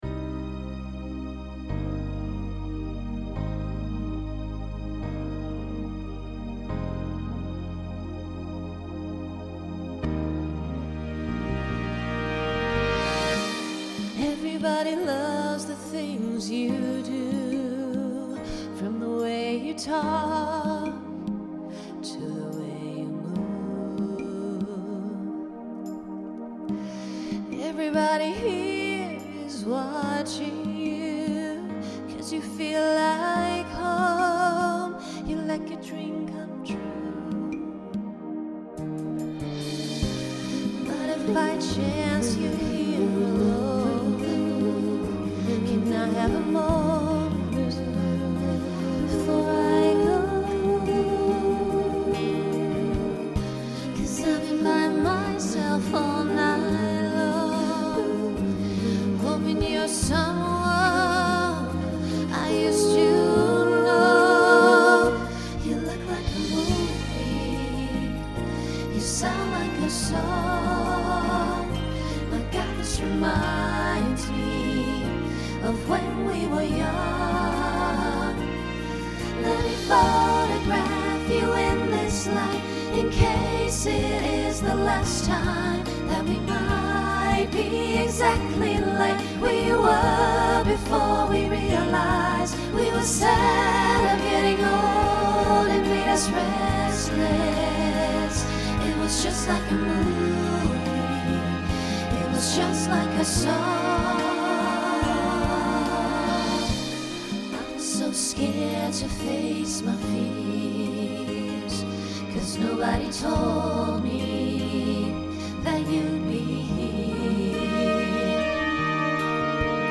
New SSA voicing for 2023.